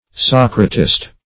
socratist - definition of socratist - synonyms, pronunciation, spelling from Free Dictionary Search Result for " socratist" : The Collaborative International Dictionary of English v.0.48: Socratist \Soc"ra*tist\, n. [Gr. ????.] A disciple or follower of Socrates.